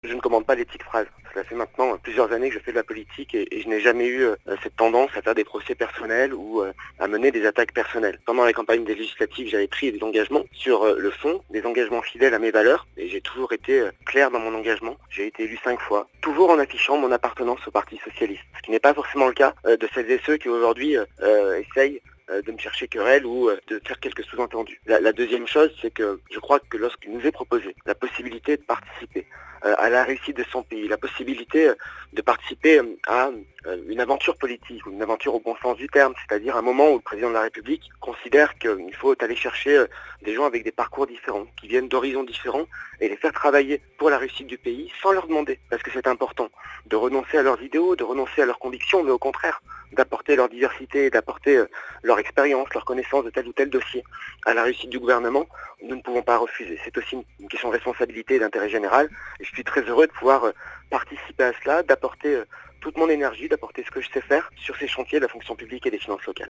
Voici quelques extraits de cet entretien téléphonique, réalisé en direct.